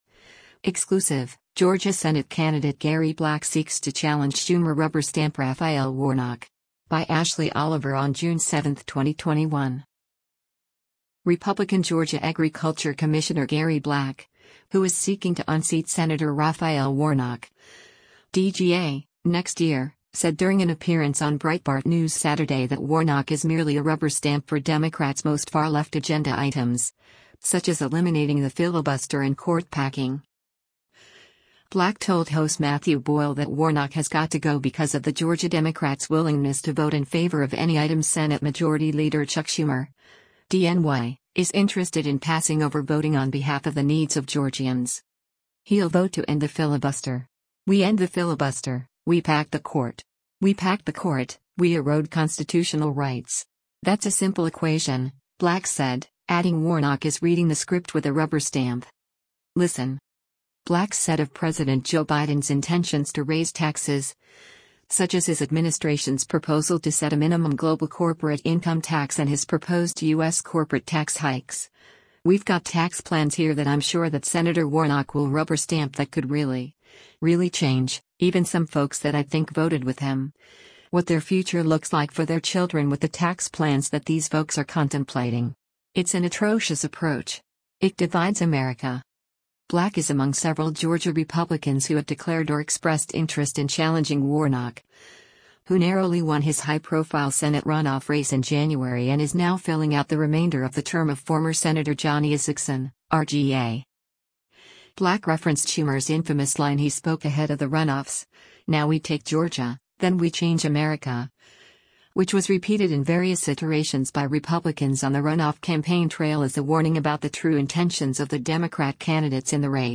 Republican Georgia Agriculture Commissioner Gary Black, who is seeking to unseat Sen. Raphael Warnock (D-GA) next year, said during an appearance on Breitbart News Saturday that Warnock is merely a “rubber stamp” for Democrats’ most far-left agenda items, such as eliminating the filibuster and court-packing.